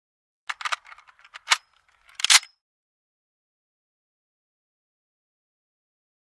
Mini-14: Magazine Insert; Single Insertion Of Magazine Into Mini 14 Automatic Rifle. Includes Insertion And Slide Pull Back. Close Up Perspective. Guns.